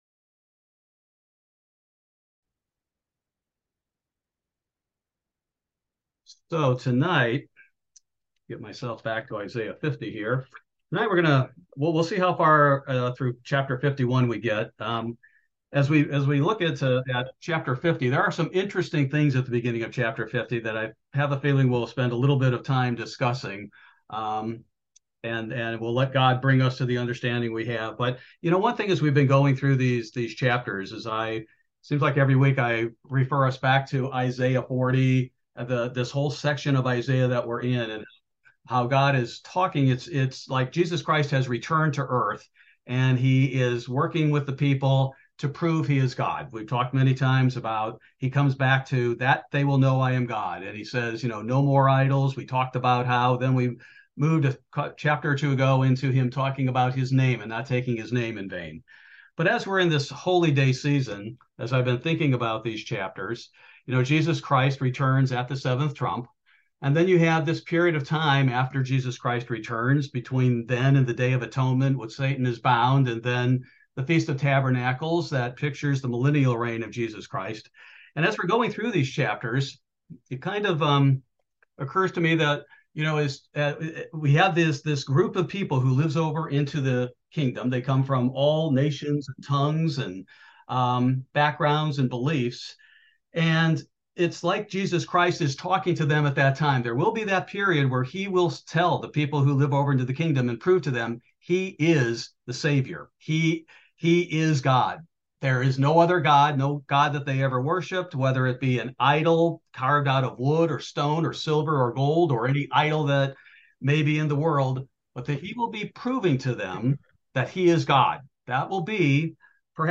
This verse by verse Bible Study focuses primarily on Isaiah 50-51: "Mother of us all;" "Listen to Me!"